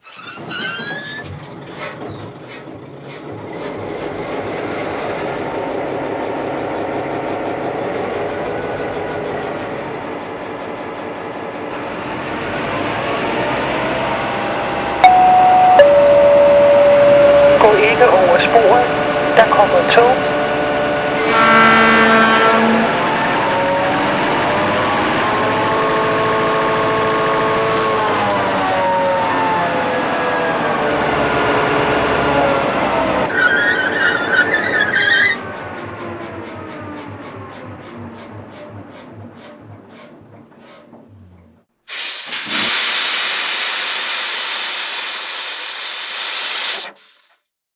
Digitalfunktionen: Fahrsound mit umfangreichen Soundfunktionen, Führerstandsbeleuchtung, dieses Fahrzeug mit mfx Decoder meldet sich an einer mfx fähigen Digitalzentrale selbst an zum Beispiel an der Mobile Station von Märklin, unterstützt das DCC Datenformat